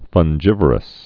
(fŭn-jĭvər-əs, fŭng-gĭv-)